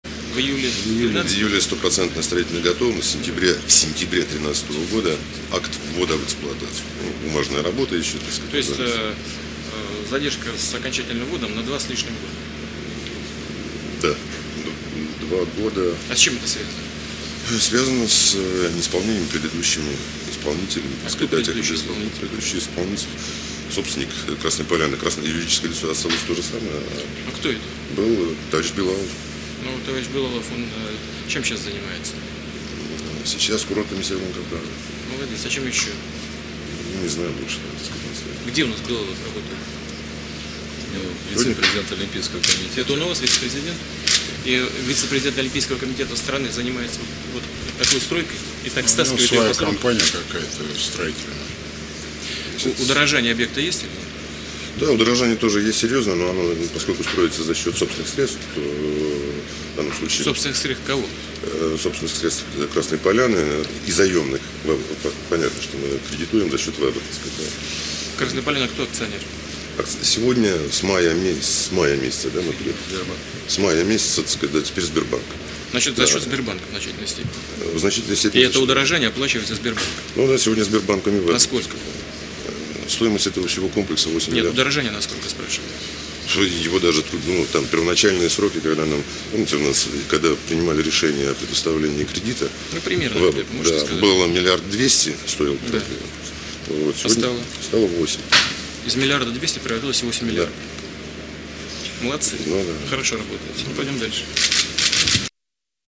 Сочиялъул Олимпиял х1аязул ралел объектазда Владимир Путинилги Дмитрий Козакиллги диалог